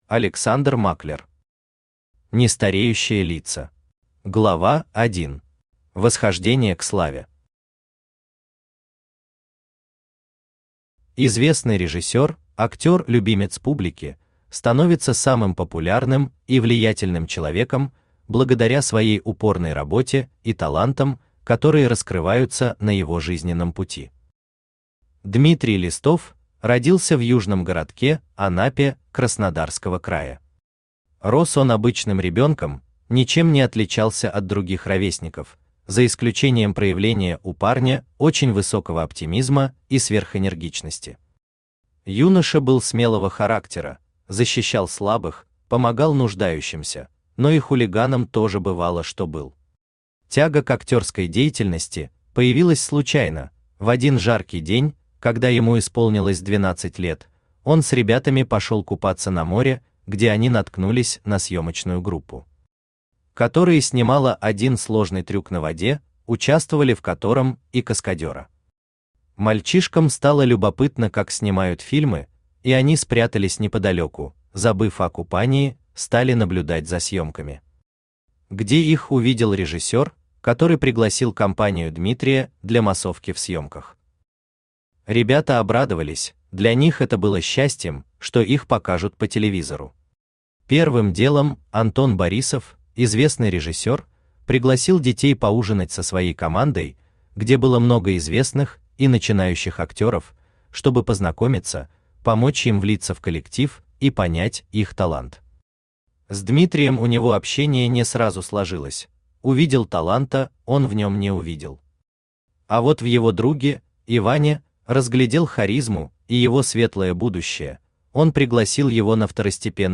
Аудиокнига Нестареющие лица | Библиотека аудиокниг
Aудиокнига Нестареющие лица Автор Александр Германович Маклер Читает аудиокнигу Авточтец ЛитРес.